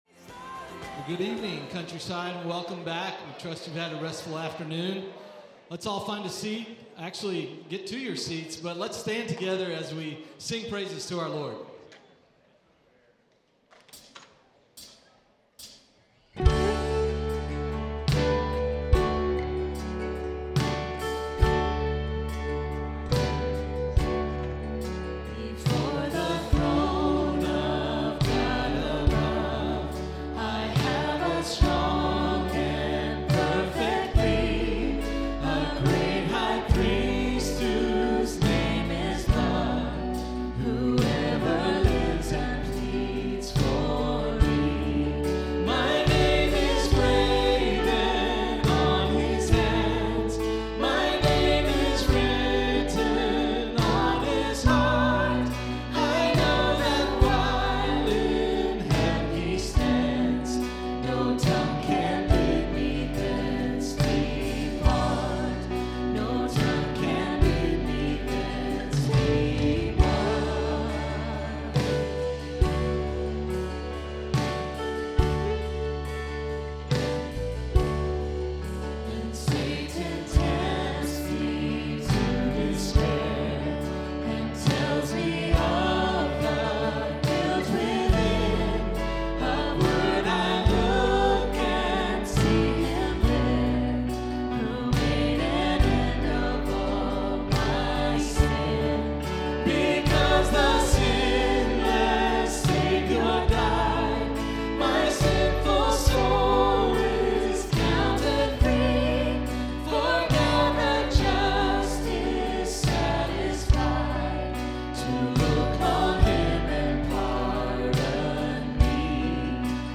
Evening Baptism Service